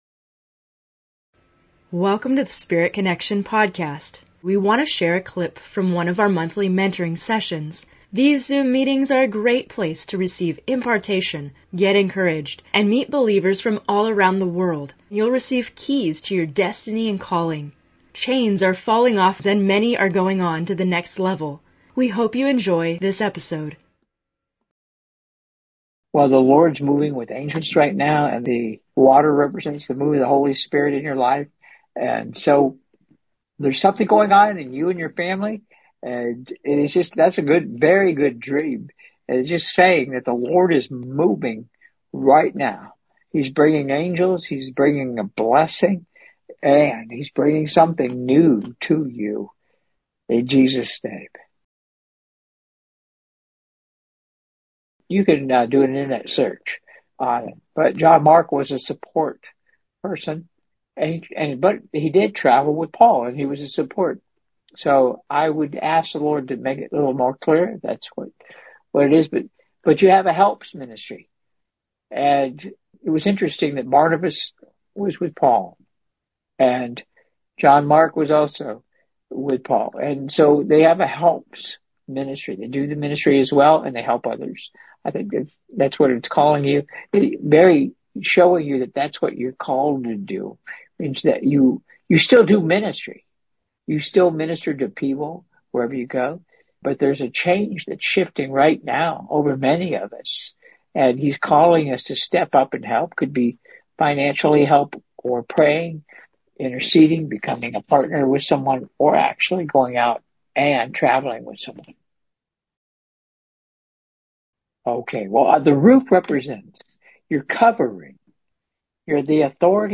In this episode of Spirit Connection, we have a special excerpt from a recent Monthly Mentoring Session Q&A.